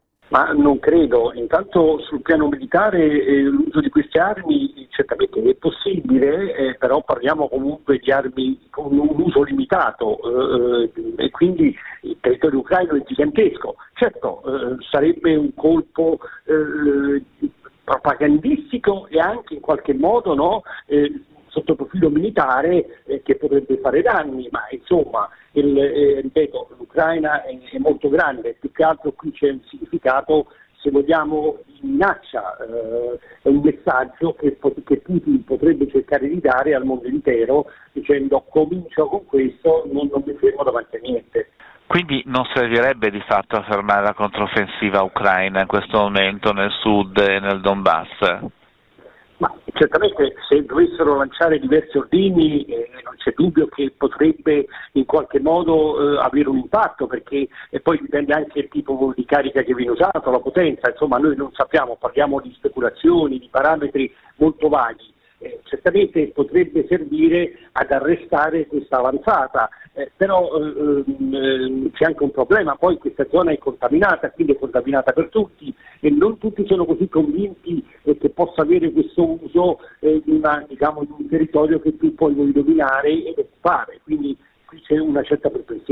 analista di questioni militari di sicurezza